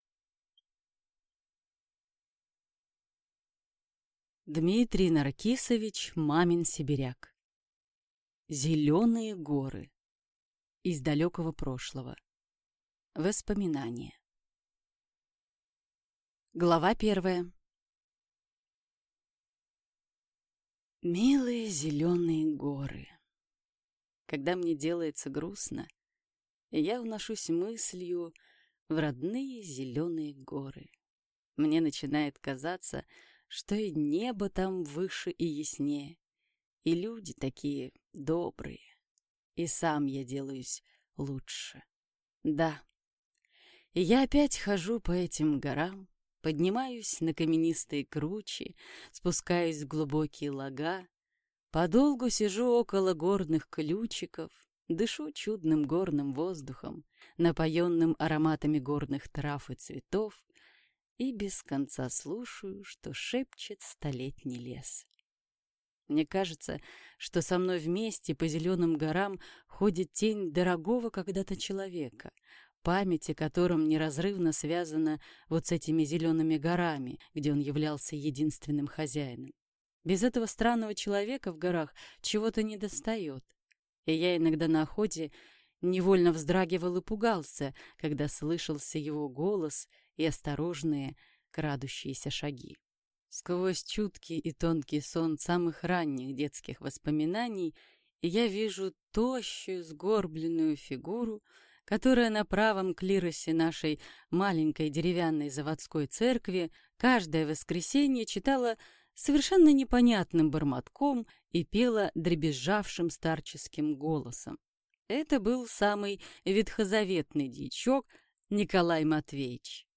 Аудиокнига Зеленые горы | Библиотека аудиокниг
Прослушать и бесплатно скачать фрагмент аудиокниги